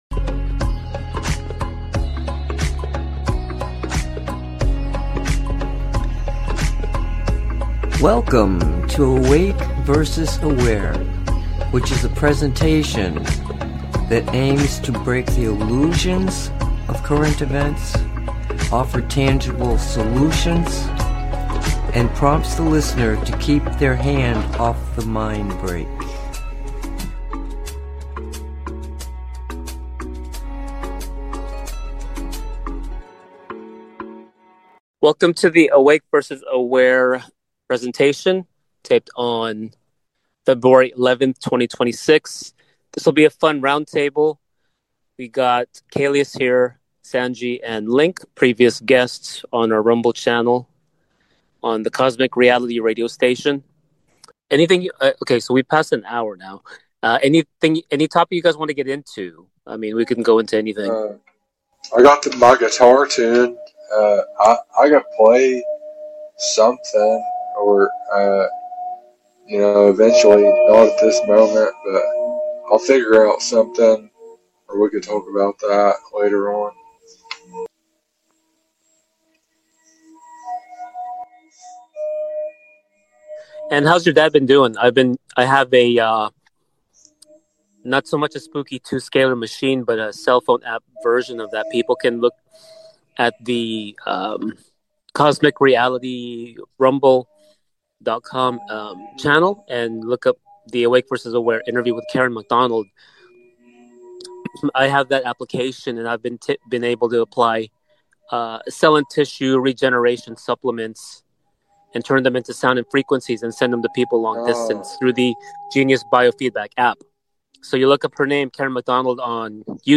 Roundtable Part 2